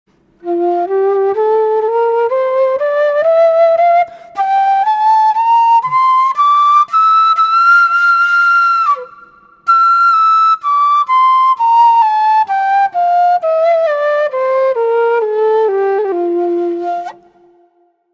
Кена (Quena, Ramos, F) Перу
Кена (Quena, Ramos, F) Перу Тональность: F
Материал: тростник